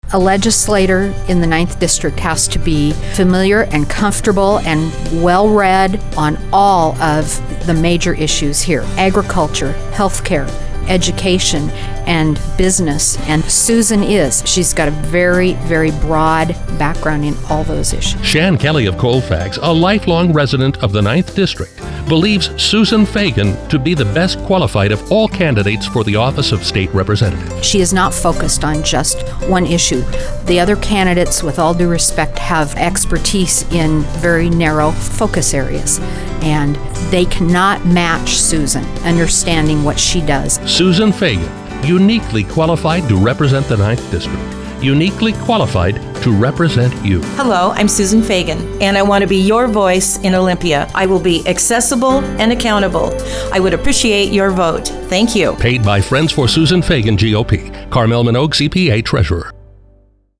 Rather, I chose to interview – in person or over the phone – the people whose recommendations might resonate with voters.
Here are three commercials from the series: